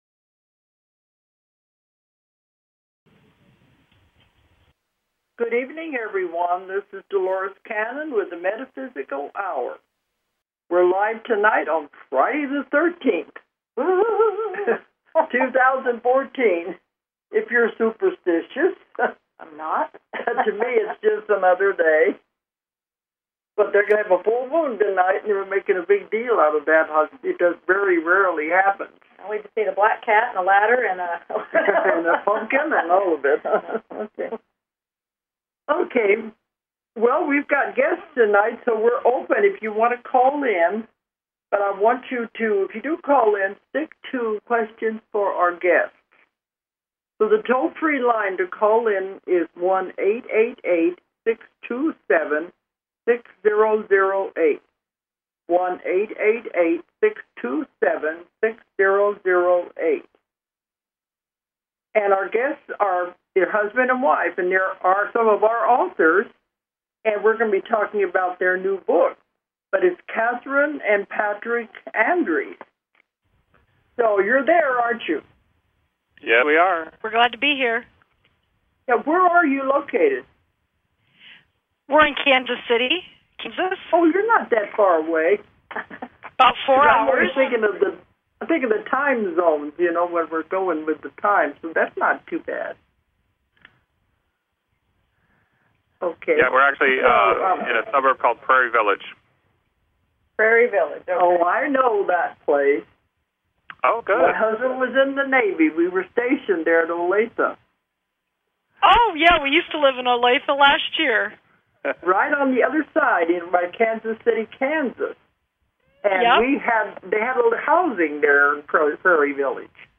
Talk Show Episode, Audio Podcast, The_Metaphysical_Hour and Courtesy of BBS Radio on , show guests , about , categorized as